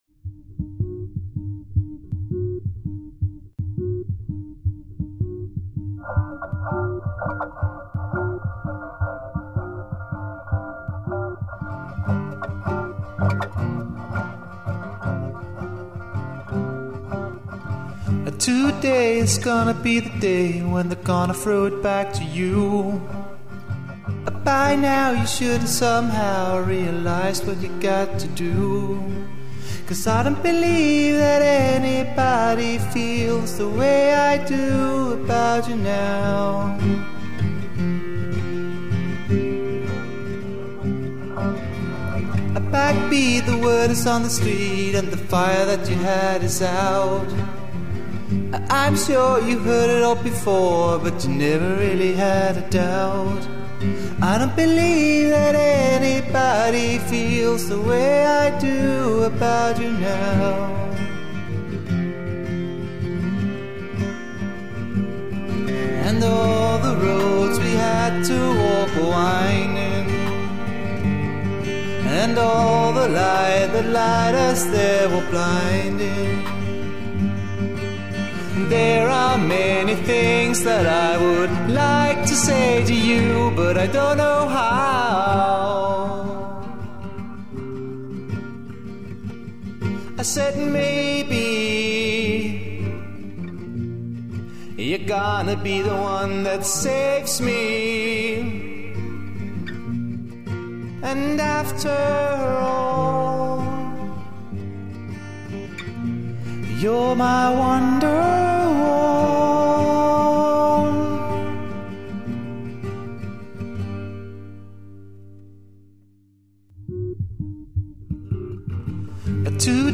Sangene er alle gemt i internet-lydkvalitet